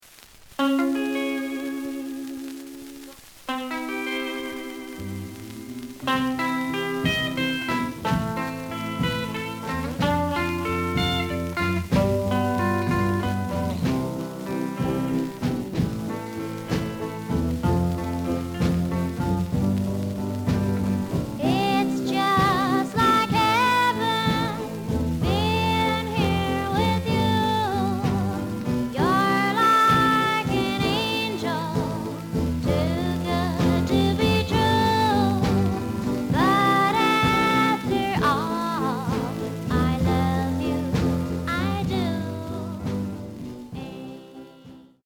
The listen sample is recorded from the actual item.
●Genre: Rhythm And Blues / Rock 'n' Roll
Slight noise on both sides.)